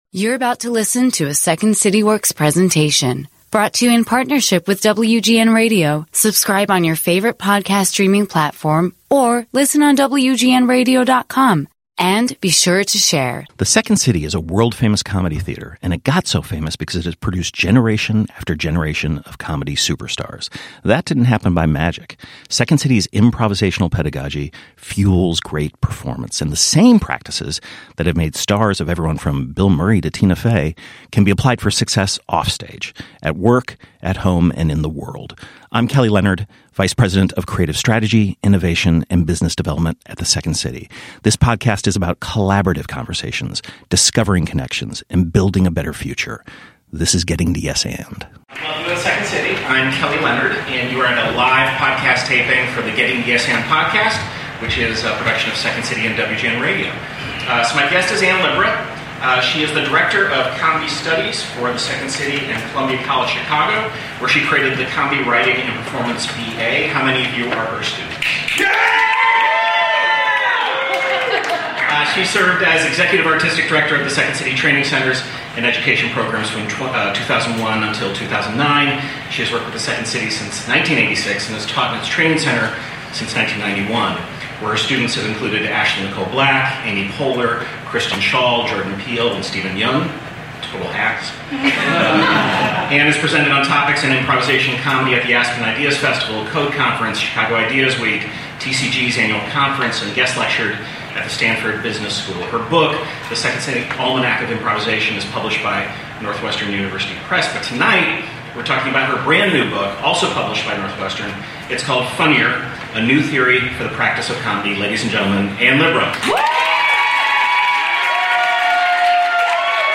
They talk in front of a live audience at The Second City.